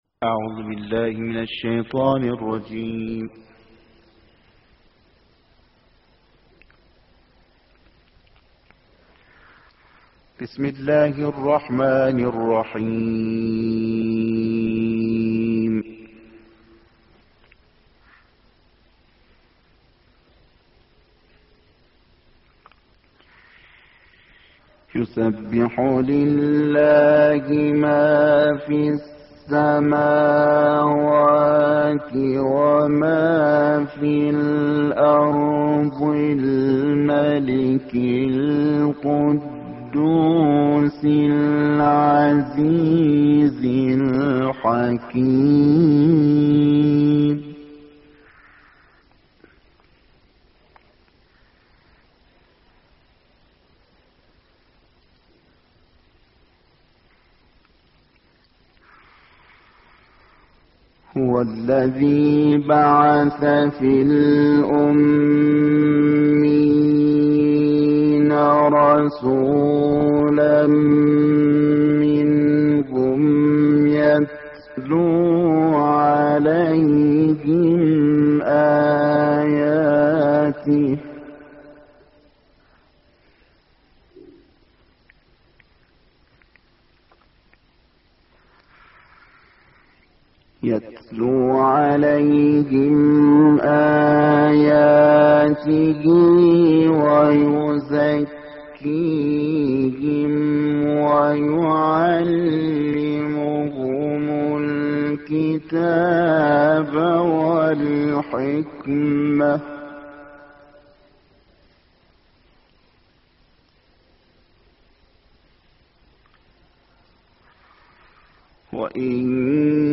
دانلود قرائت سوره جمعه